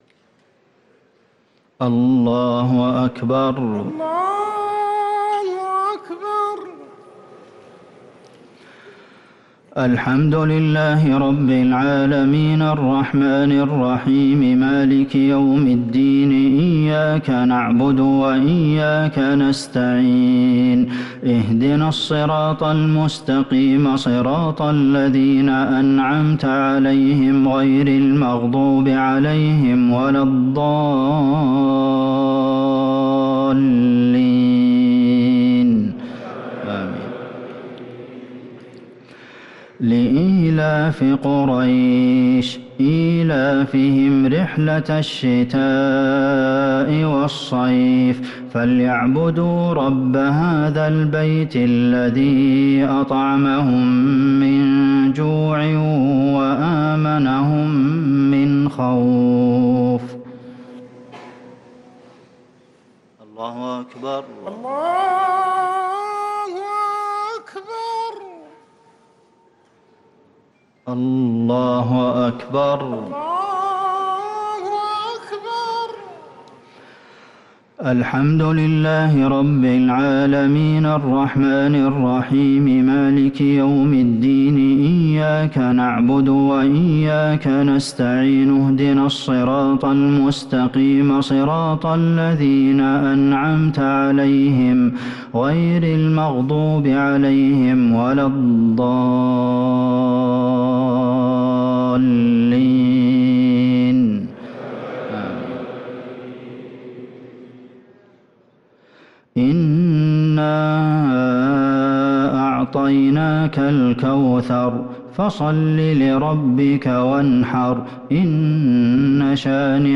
الشفع و الوتر ليلة 12 رمضان 1444هـ | Witr 12st night Ramadan 1444H > تراويح الحرم النبوي عام 1444 🕌 > التراويح - تلاوات الحرمين